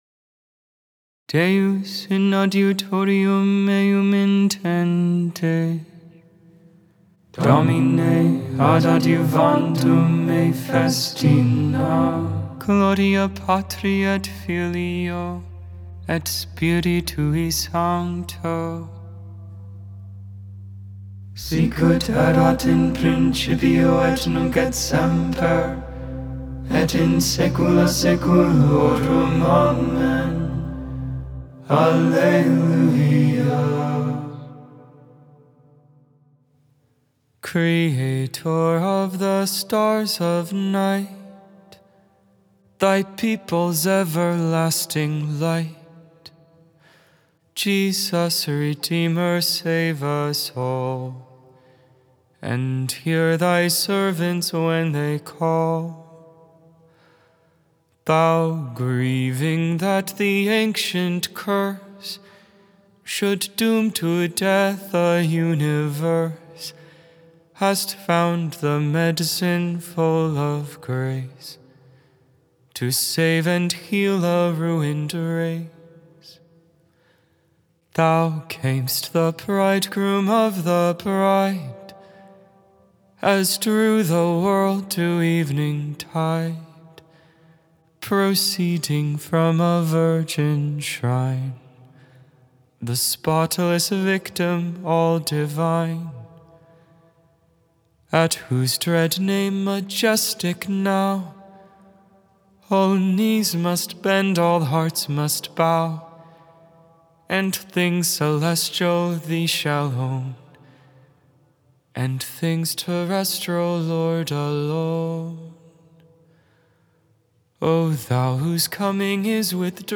Vespers, Evening Prayer for the 2nd Tuesday in Advent, December 10th, 2024.